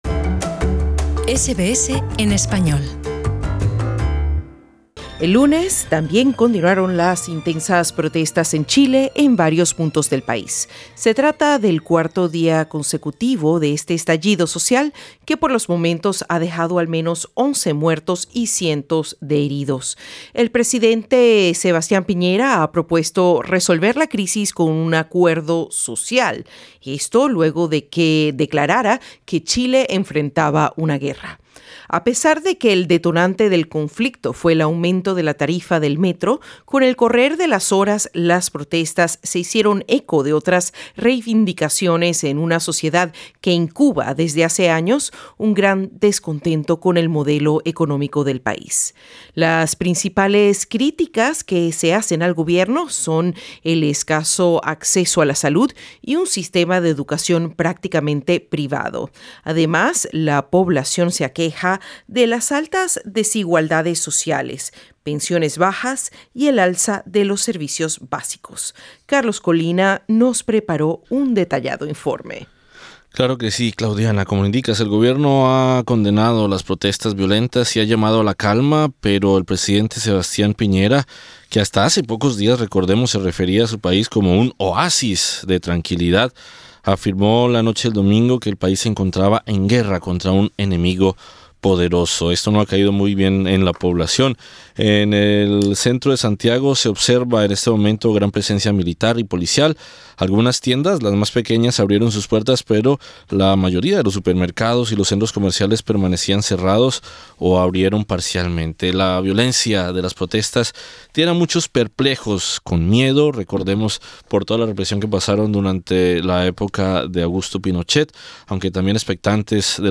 Entrevistados: Diputado Pablo Vidal, Senador Alejandro Navarro